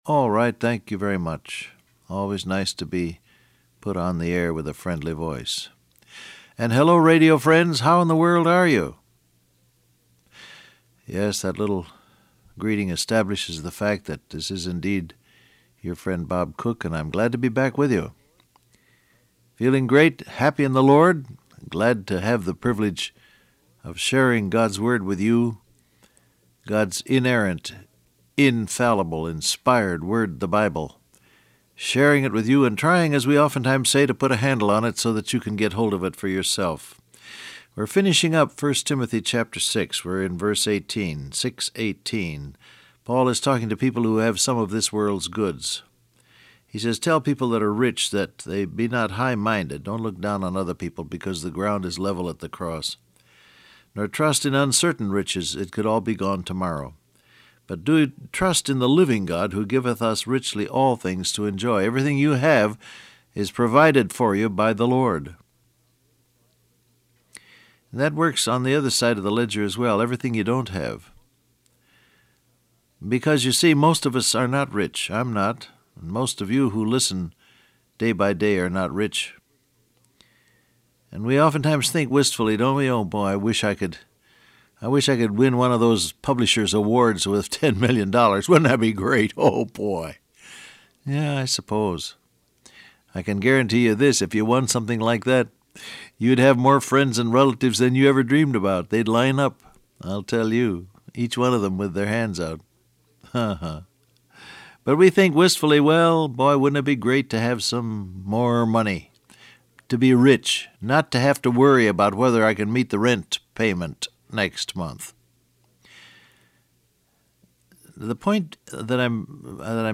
Download Audio Print Broadcast #6775 Scripture: 1 Timothy 6:17-18 , Malachi 3:10 Topics: Faithful , Wealth , Rich , Tithe Transcript Facebook Twitter WhatsApp Alright, thank you very much.